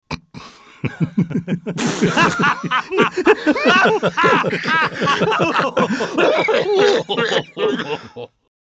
rire.mp3